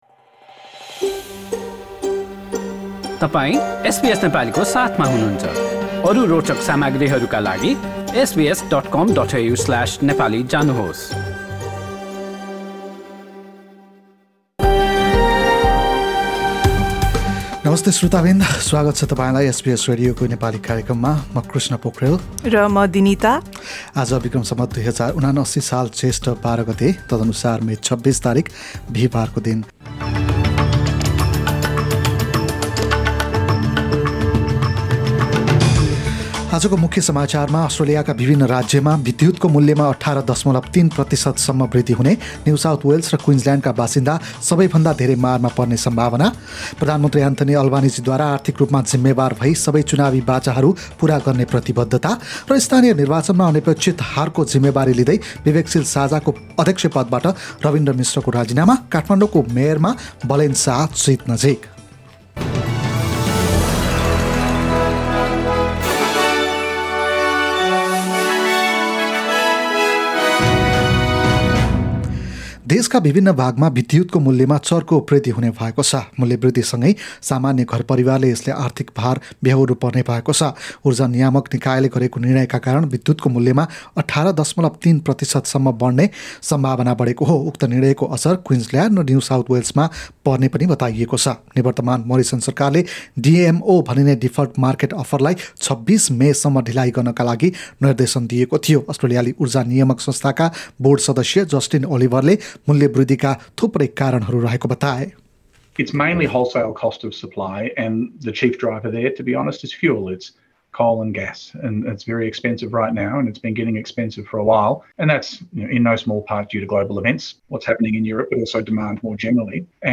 एसबीएस नेपाली अस्ट्रेलिया समाचार: बिहीबार २६ मे २०२२